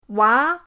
wa2.mp3